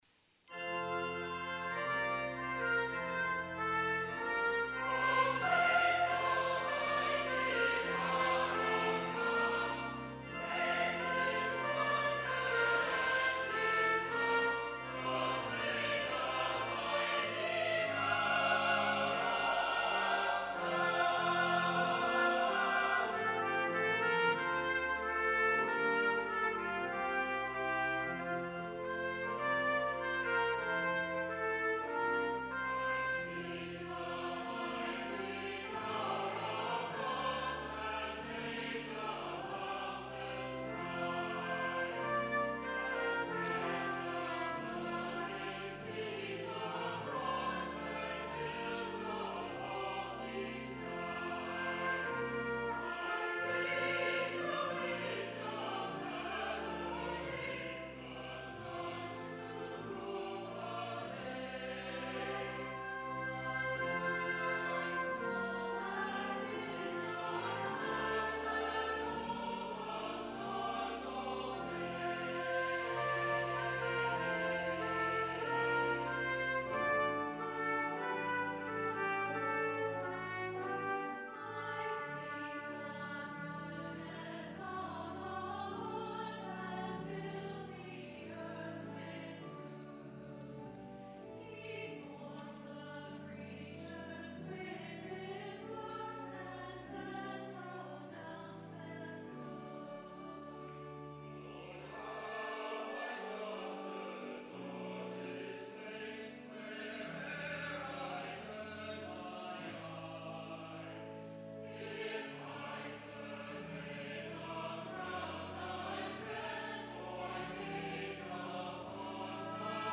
Chancel Choir
trumpet
organ